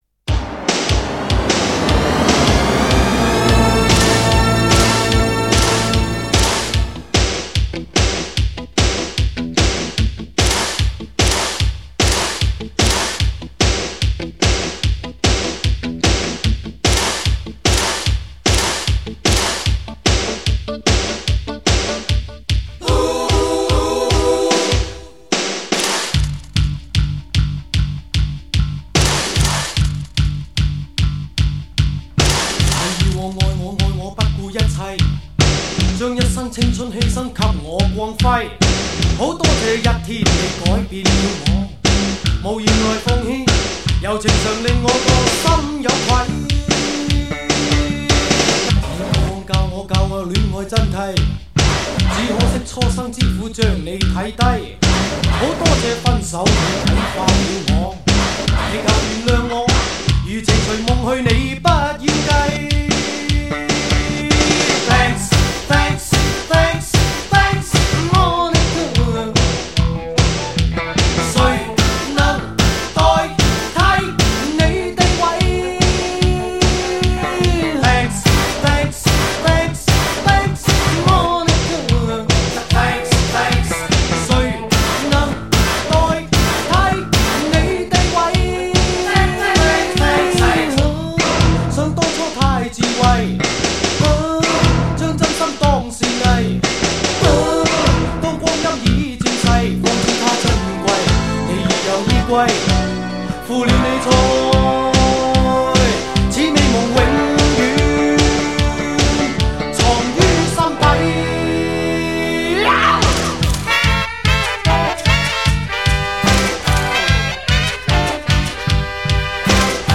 2CD120分钟80年代最全盛时的REMIX经典